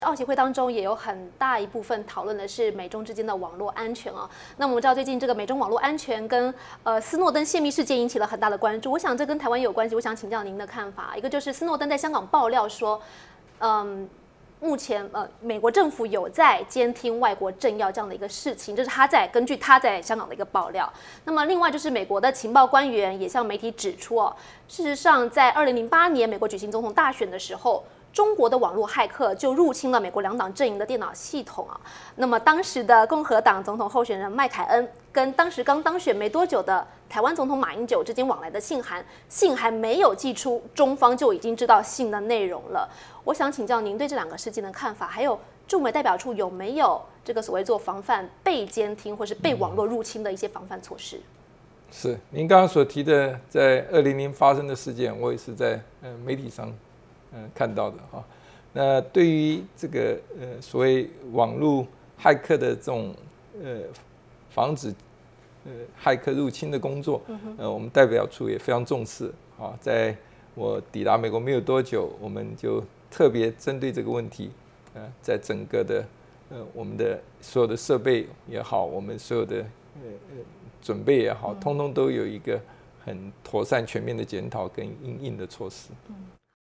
《海峡论谈》VOA专访节选: 台湾驻美代表金溥聪谈网络黑客